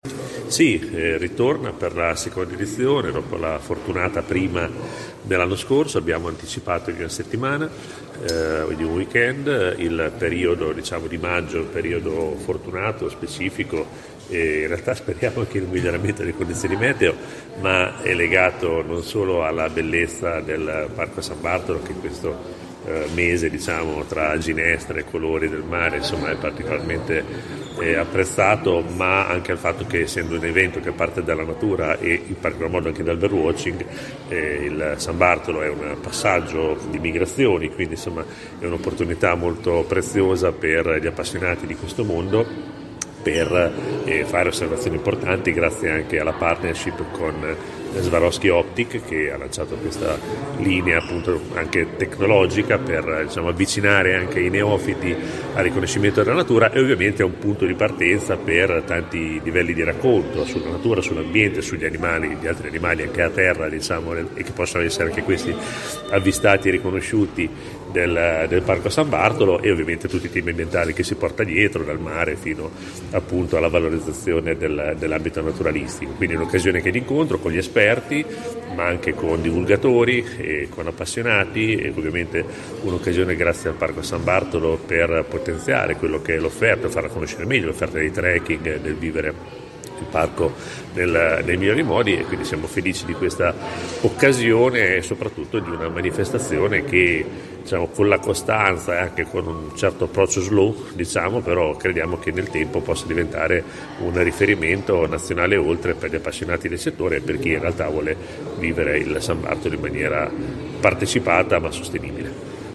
Ce ne parla ai nostri microfoni: Daniele Vimini, Assessore alla Bellezza.